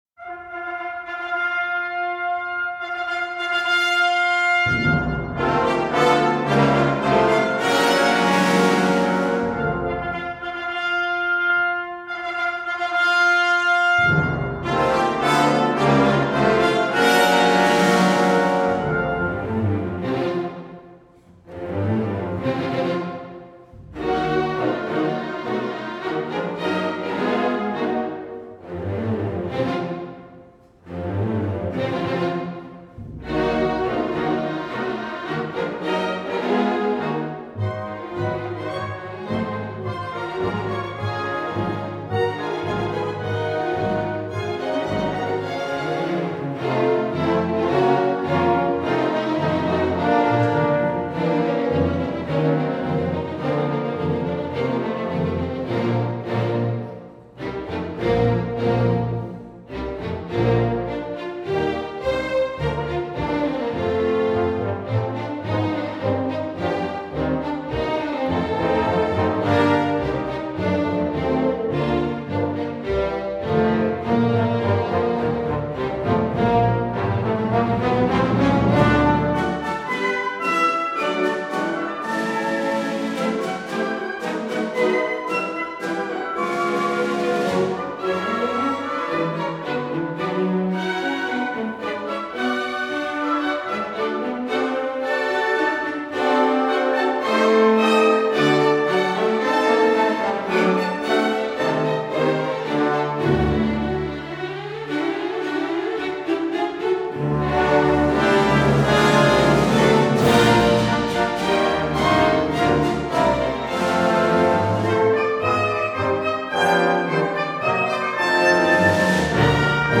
must all stand aside before his American Civil War march
opulent work